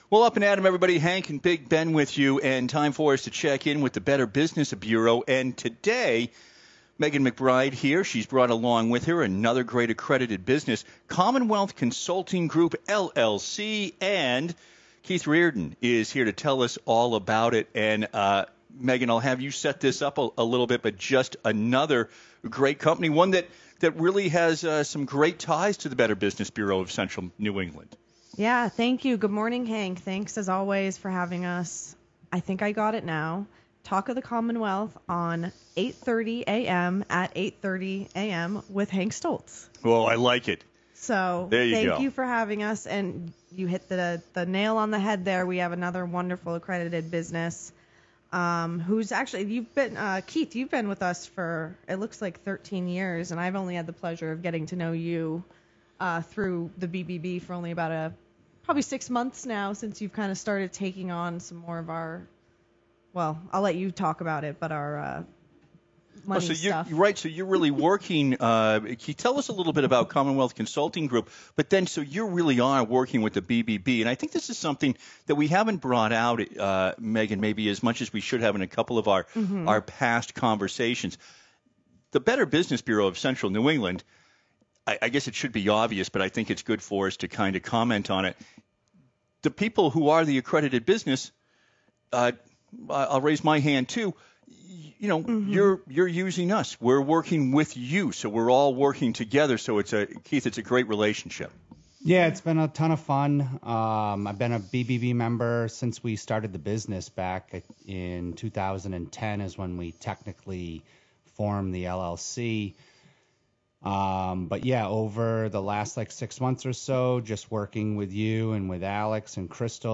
CCG discusses Merchant Services on Radio Worcester